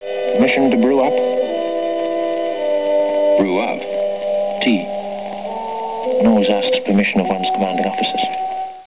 And, of course, an actor who knows how to use his voice as well as Gary Raymond can make even the oddest line sophisticated and memorable.